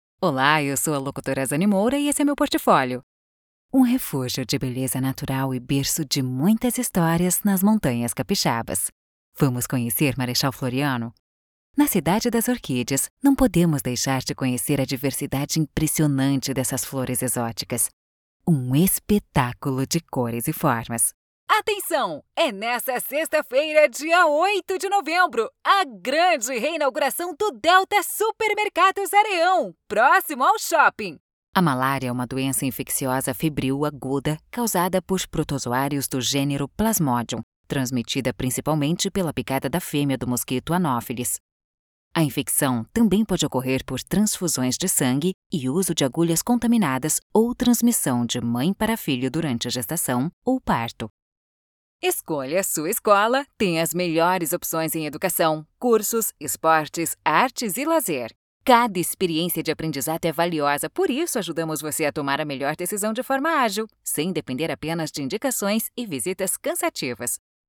Spot Comercial
Animada